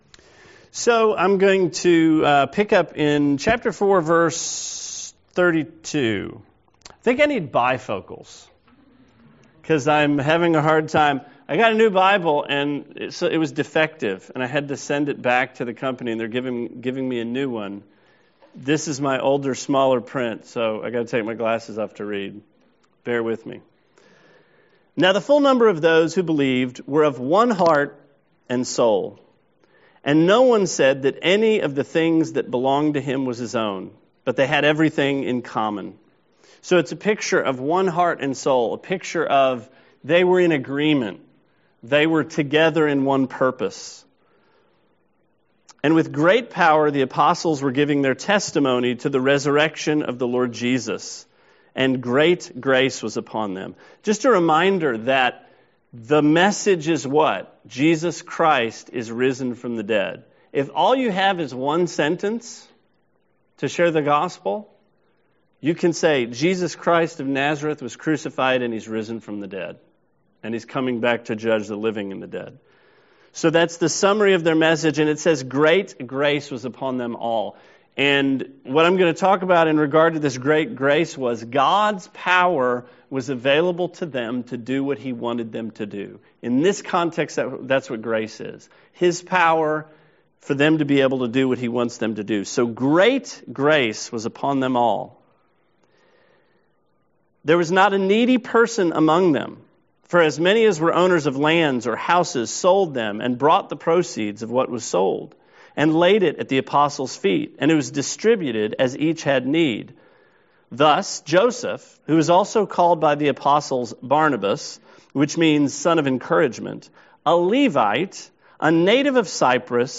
Sermon 4/1: Acts 4:32-5:16